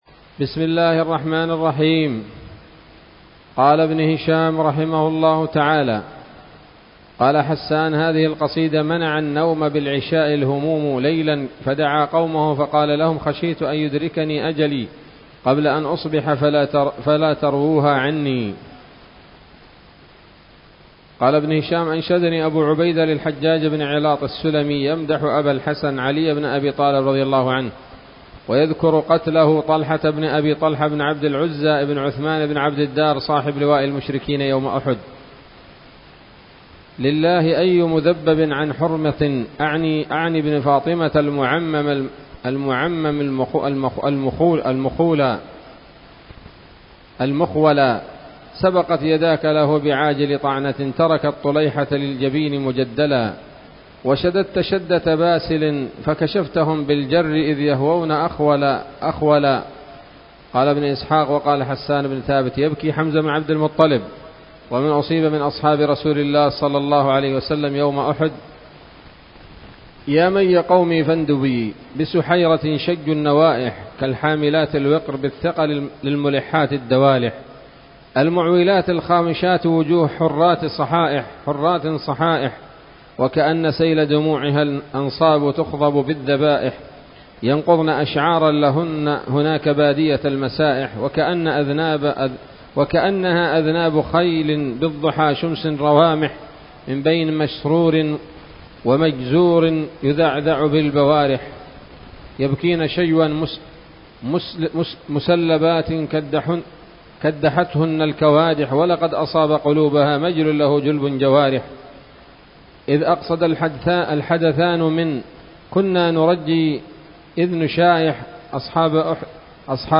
الدرس الحادي والثمانون بعد المائة من التعليق على كتاب السيرة النبوية لابن هشام